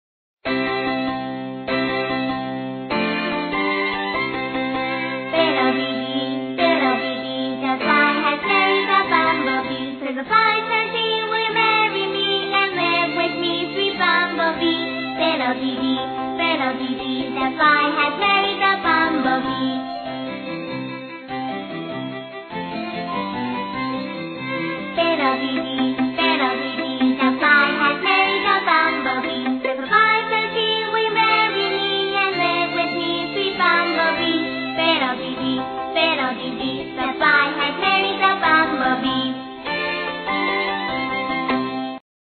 在线英语听力室英语儿歌274首 第39期:Fiddle Dee Dee的听力文件下载,收录了274首发音地道纯正，音乐节奏活泼动人的英文儿歌，从小培养对英语的爱好，为以后萌娃学习更多的英语知识，打下坚实的基础。